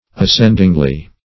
As*cend"ing*ly, adv.